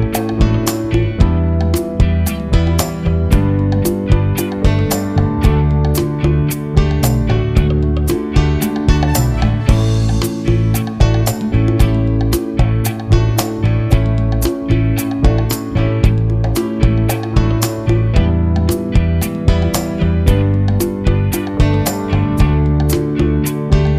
Key of D Pop (1970s) 4:30 Buy £1.50